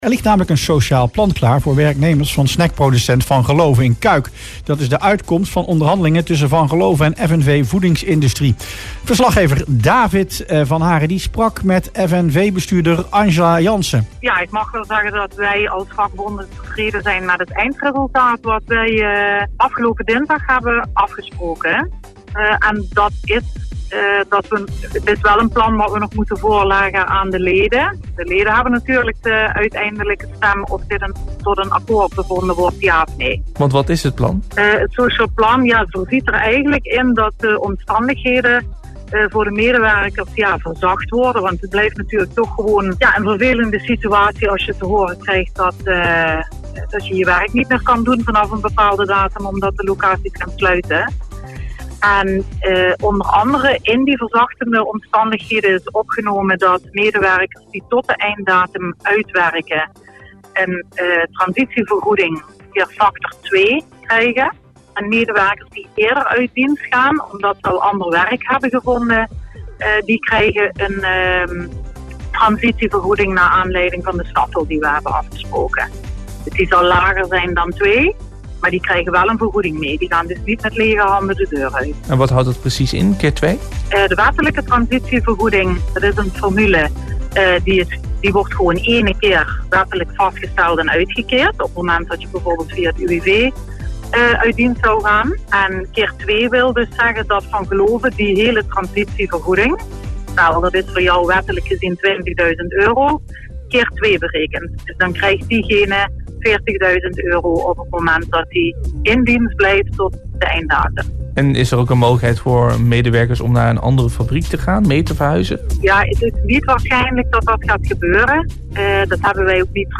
in gesprek met